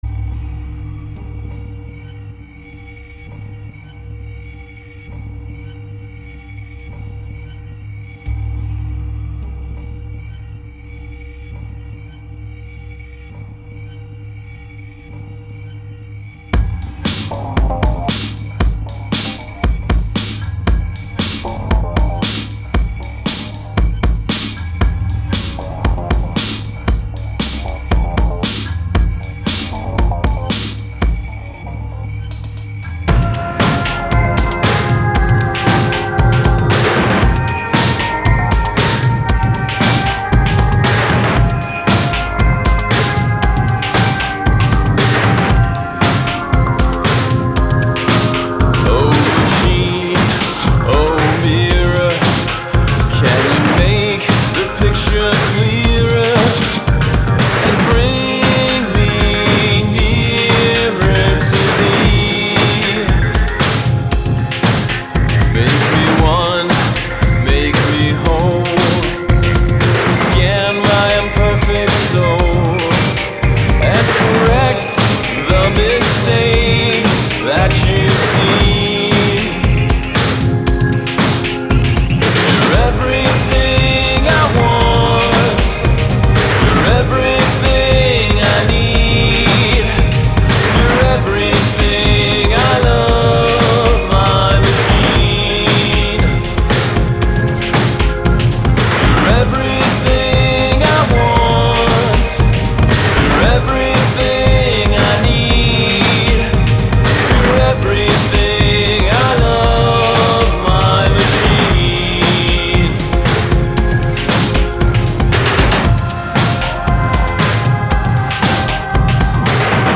It's sort of unabashedly pop and (new) romantic, which is appropriate given that I wanted to make a synth-pop record.